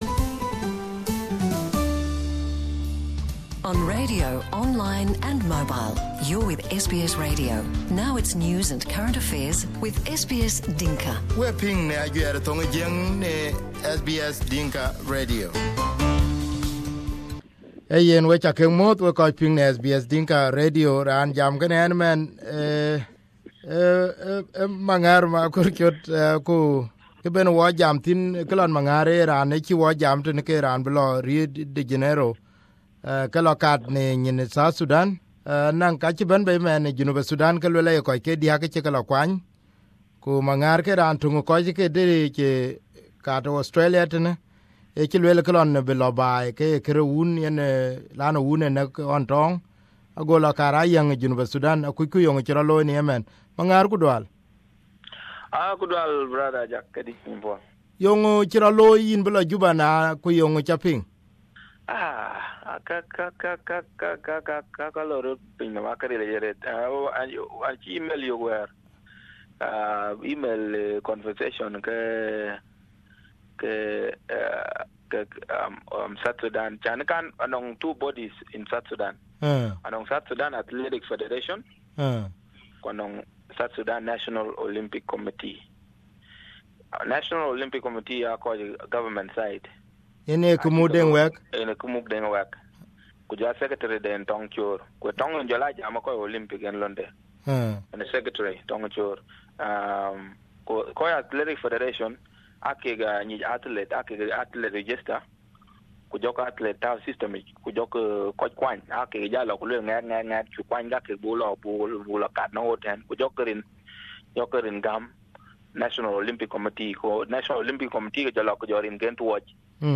We interviewed him after the news and here is what he has to say on SBS Dinka Radio.